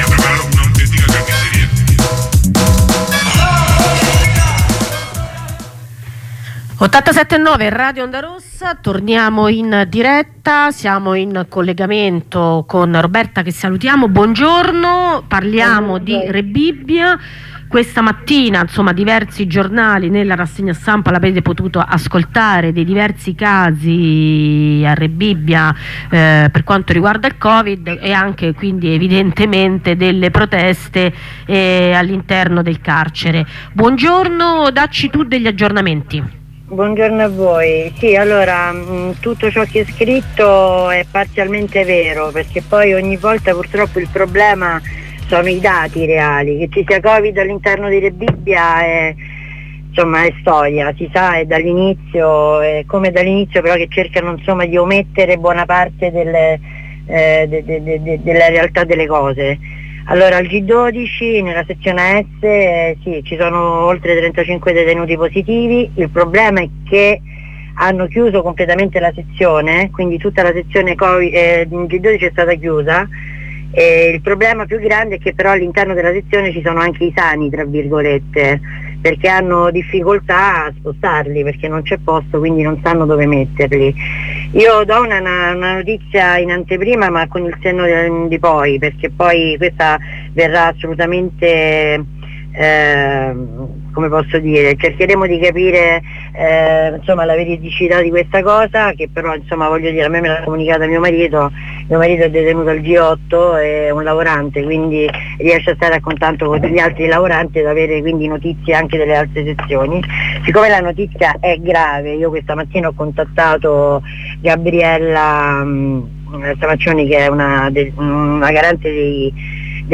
Da lunedì i detenuti hanno iniziato lo sciopero della fame e ieri mattina hanno iniziato a danneggiare le loro stanze detentive. Facciamo il punto della situazione con una compagna.